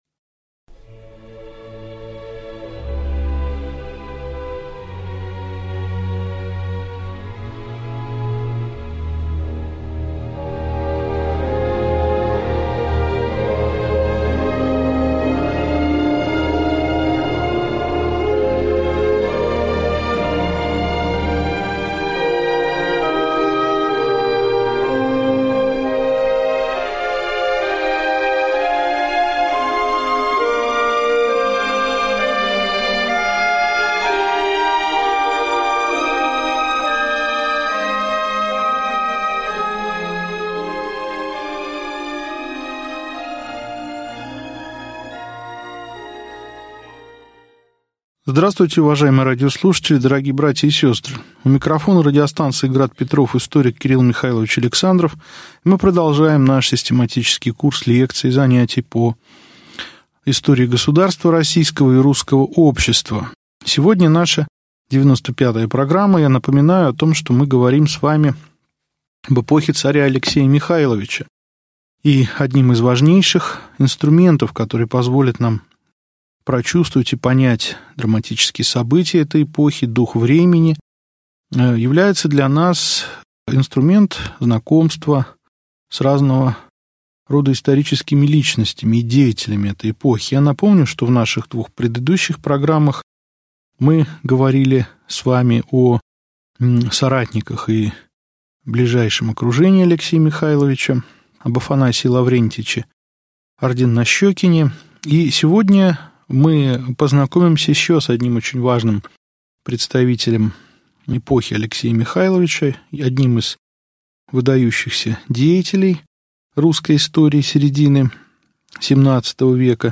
Аудиокнига Лекция 95. Ф.М. Ртищев | Библиотека аудиокниг